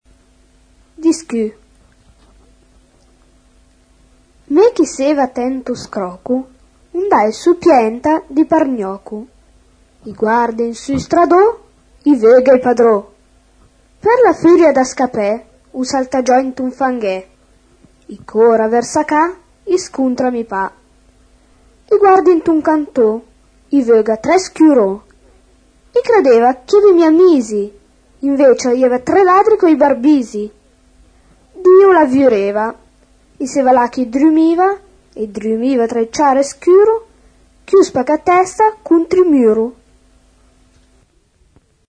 rima burlesca